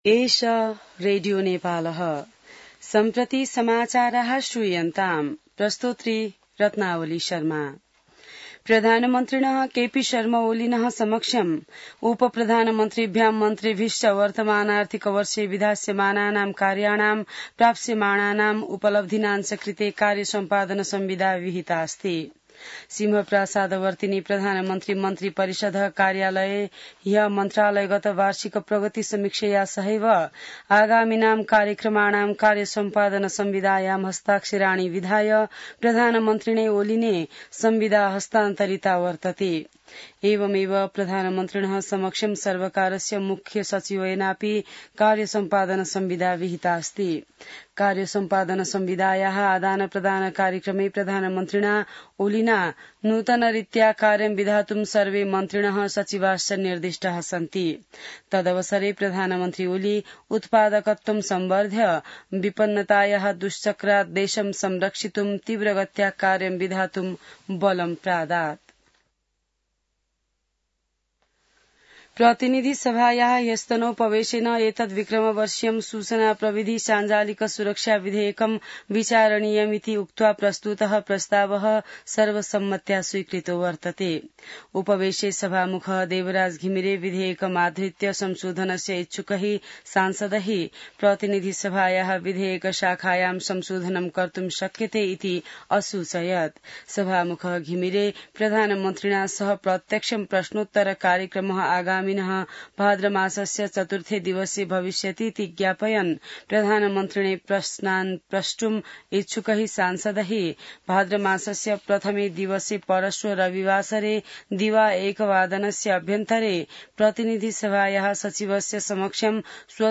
संस्कृत समाचार : ३० साउन , २०८२